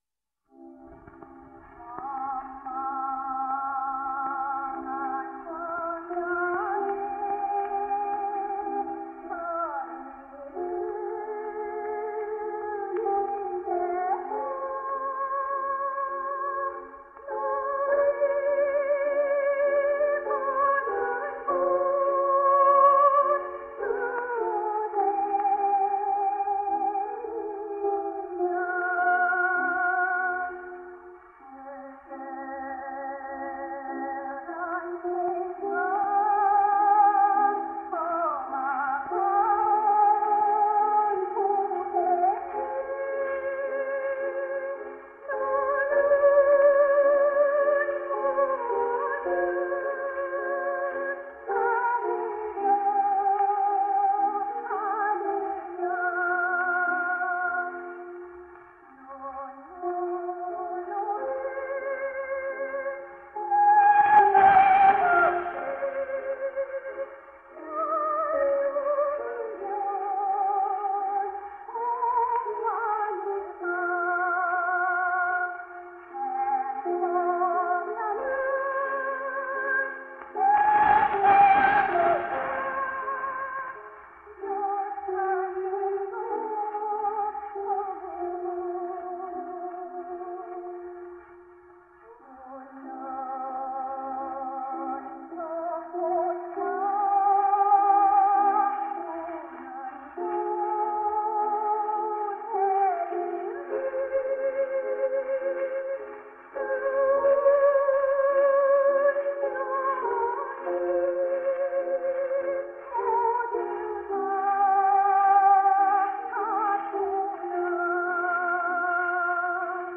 소프라노
오사카에서 녹음되었으며